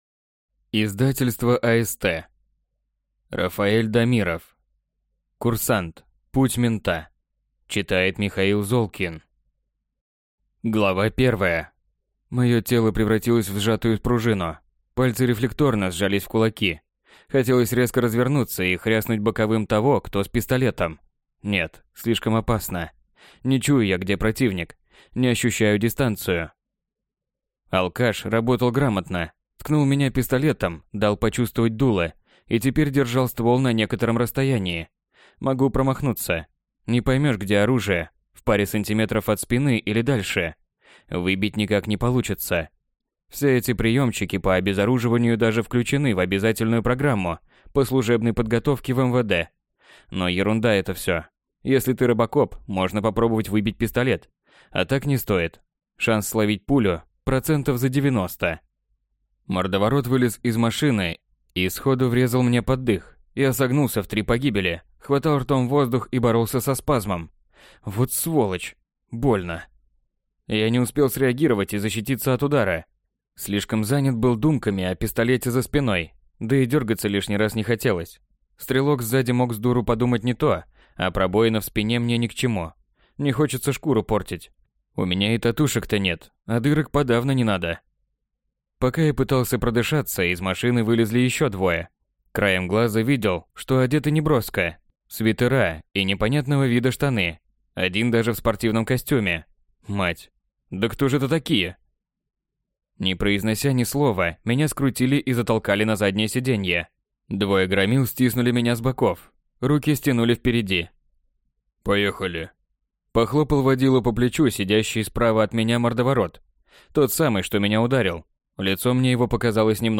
Аудиокнига Курсант: путь мента | Библиотека аудиокниг